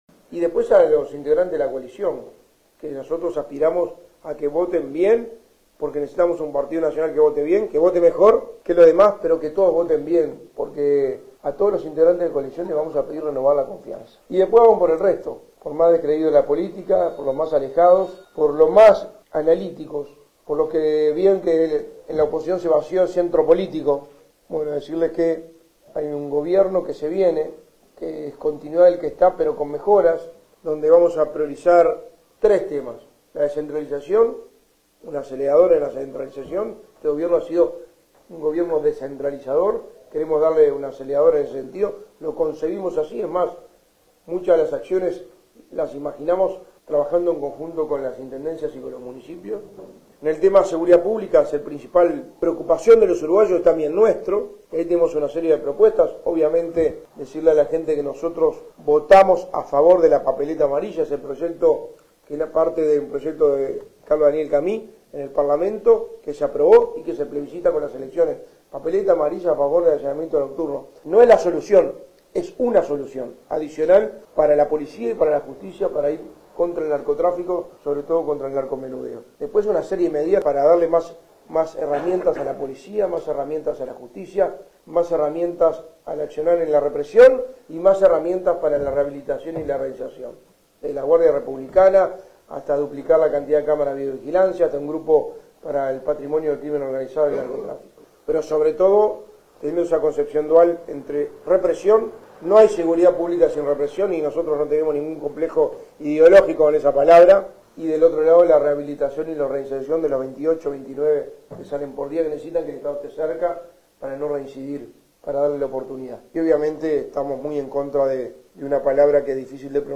El candidato a la Presidencia por el PN, Álvaro Delgado, realizó este jueves una visita a San José de Mayo, brindando una rueda de prensa en el Hotel Centro, antes de mantener una reunión con la dirigencia política departamental, en la Casa del Partido.